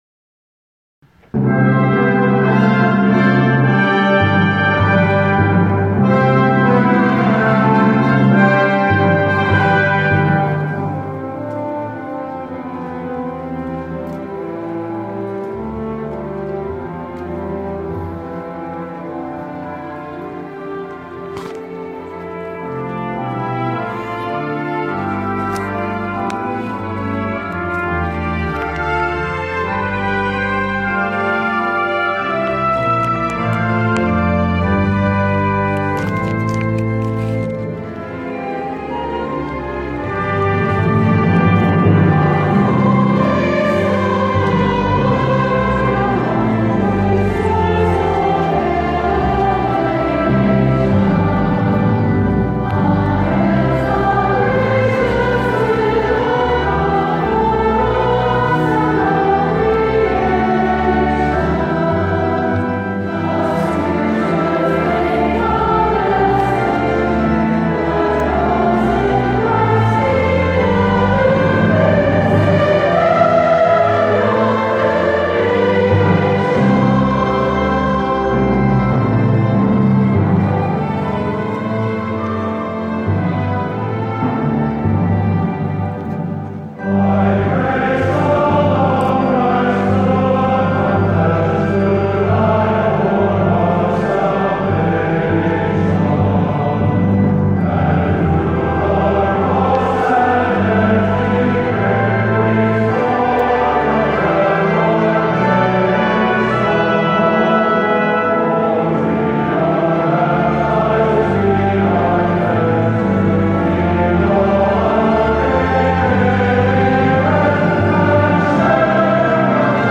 commissioned by Concordia–NY for its Hymn Festival at Carnegie Hall
Version 2: SATB divisi, Flute, Brass Quartet, Timpani, Organ, and Assembly
gods-only-son-the-word-alone-carnegie-hall.mp3